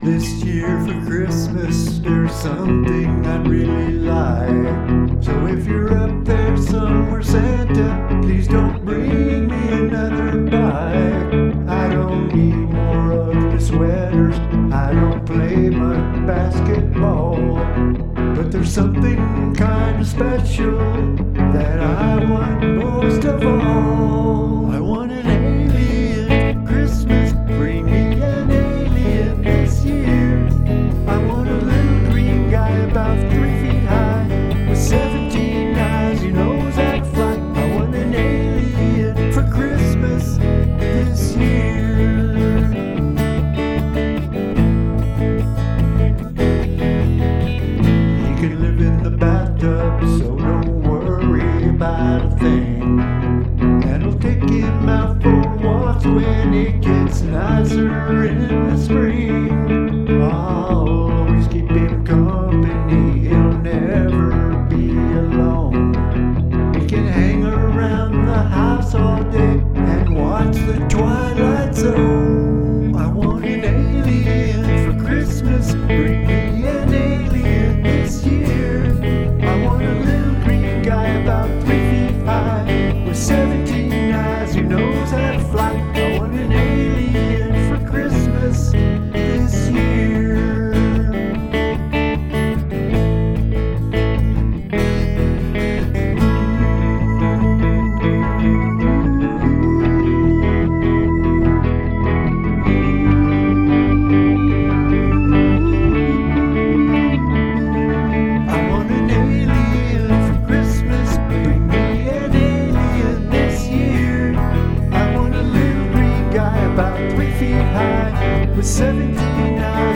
hand clapping